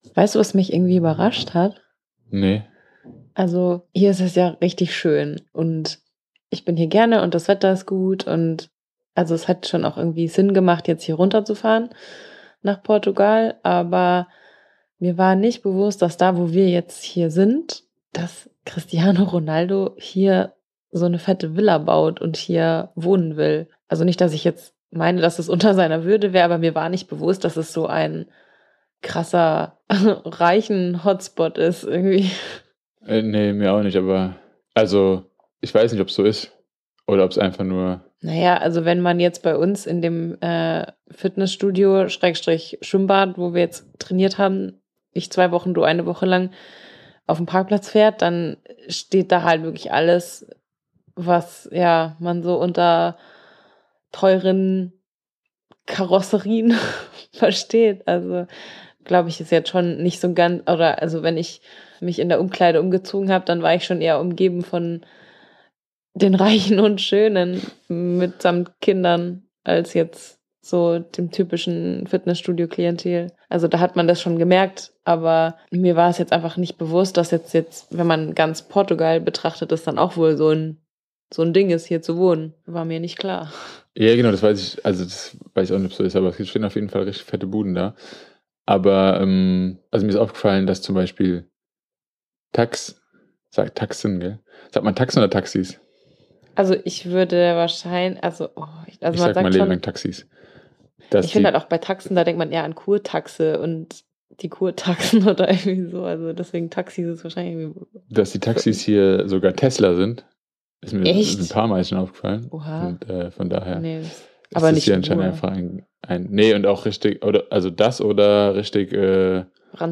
Für alle, die keine Ahnung haben, weil sie sich doch eher für Triathlon interessieren (sympathisch), hier die Auflösung: Wir halten uns gerade im Süden Portugals aus, wo wir noch ein paar letzte Sonnenstrahlen einfangen, bevor der Winter so richtig beginnt. Alles, was sich hier so zuträgt, haben wir Euch in dieser Folge zusammengetragen.